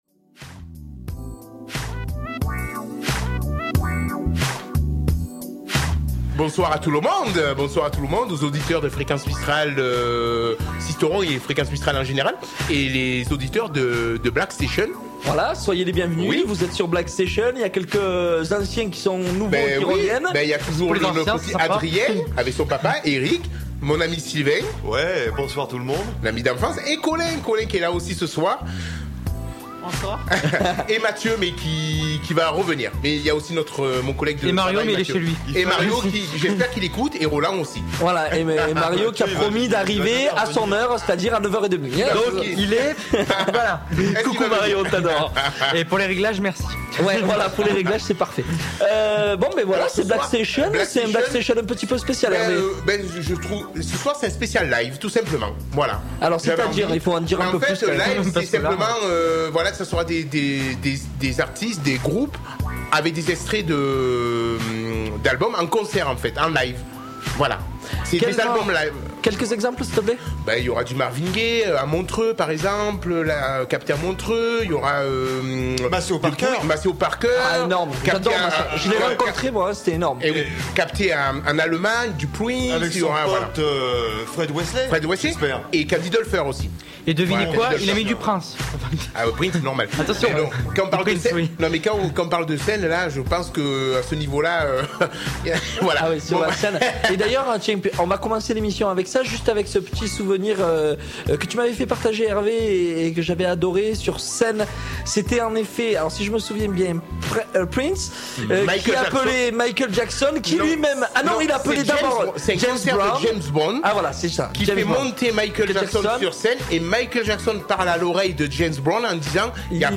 une émission pleine de versions inédites jouées sous les feux des projecteurs pour le plaisir de nos oreilles